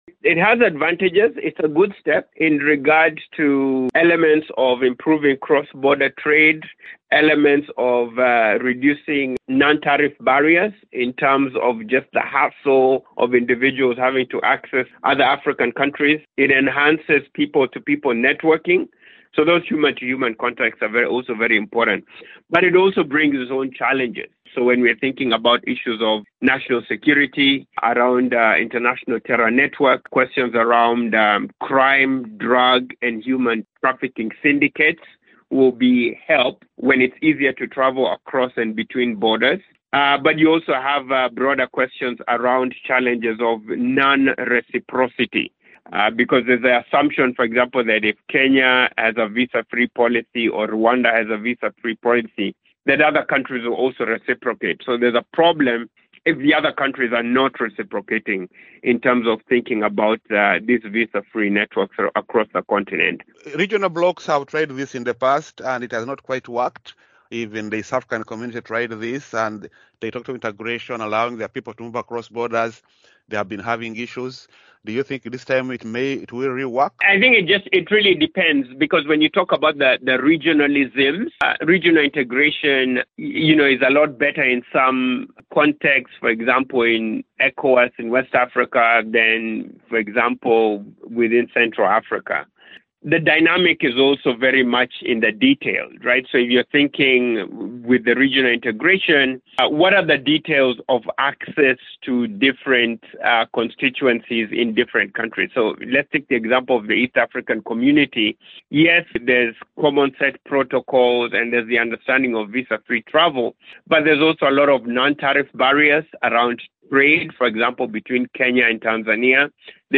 Rwanda says it will allow all Africans to travel visa-free to the country. Other countries that allow Africans to enter without visas are Gambia, Benin and Seychelles. Kenya’s President William Ruto has also announced plans to allow all Africans to travel to the East African nation visa-free by December 31. Political analyst